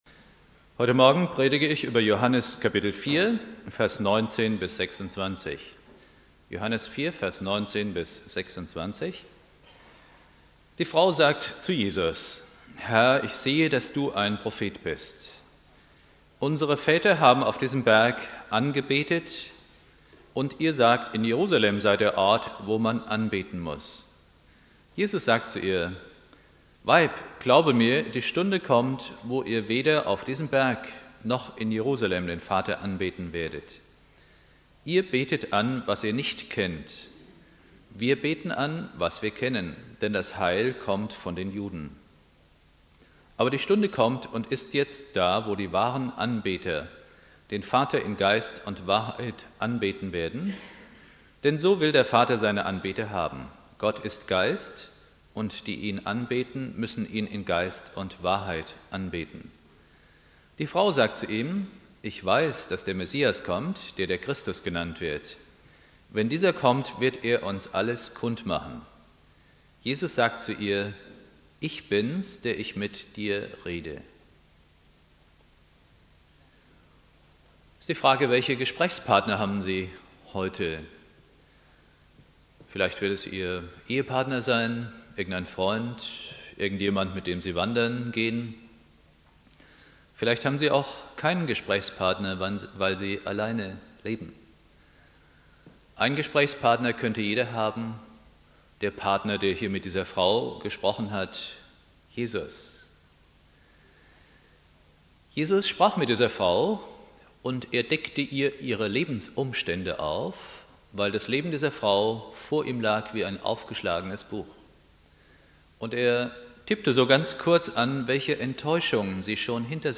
Pfingstmontag Prediger